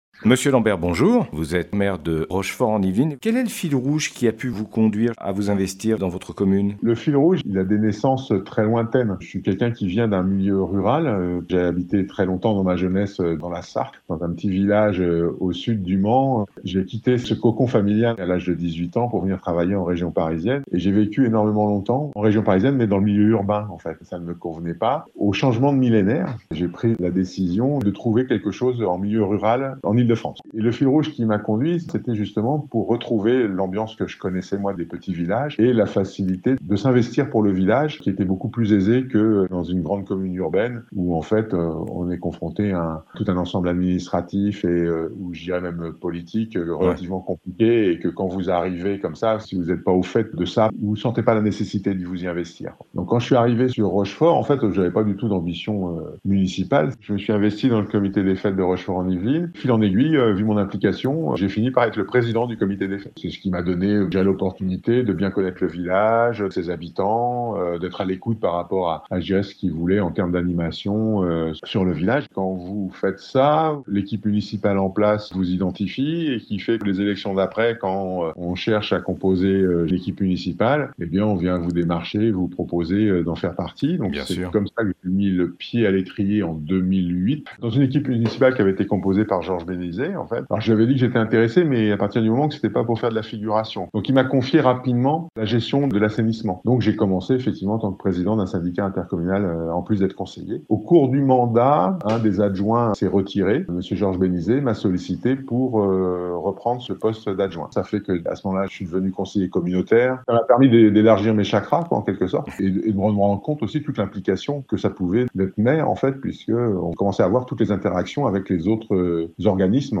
Interviw de Monsieur Lambert Maire de rochefort en Yvelines - Radio RVE